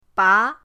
ba2.mp3